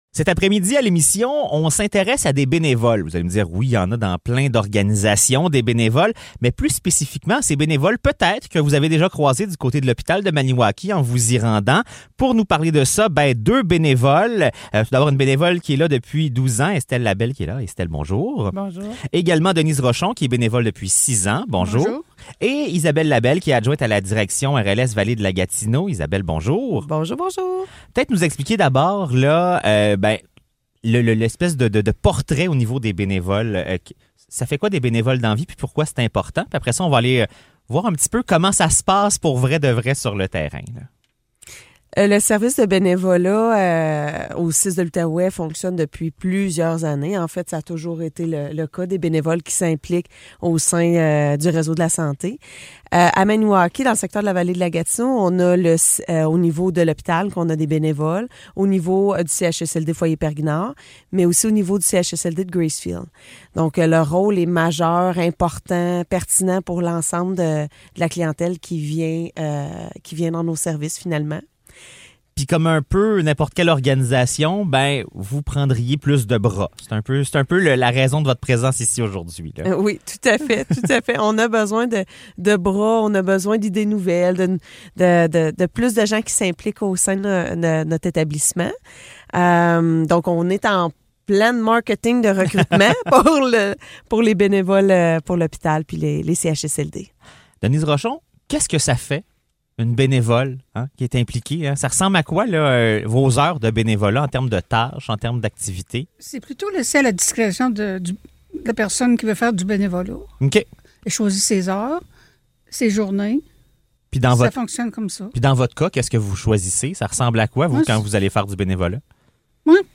Entrevue sur les bénévoles du CISSS de l'Outaouais
entrevue-sur-les-benevoles-du-cisss-de-loutaouais.mp3